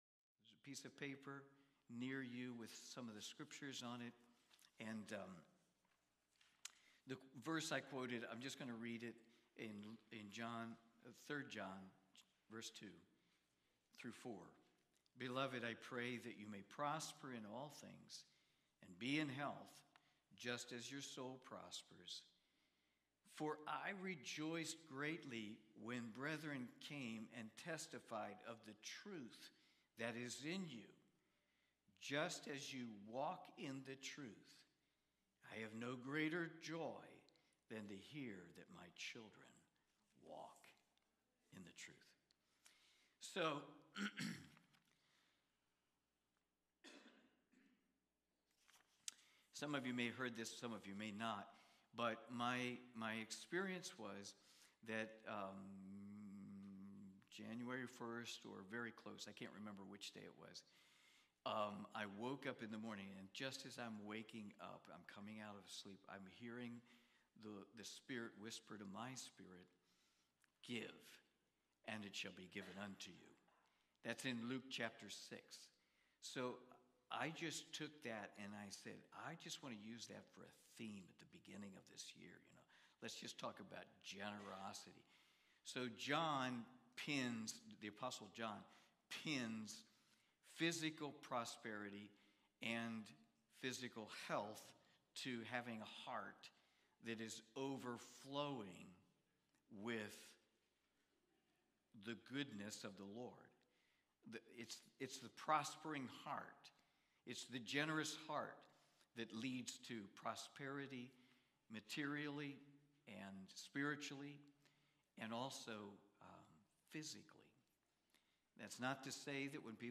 3 John Watch Listen Save Cornerstone Fellowship Sunday morning service, livestreamed from Wormleysburg, PA.